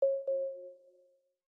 Knock Notification 8.wav